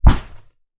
Punching Bag Rhythmic C.wav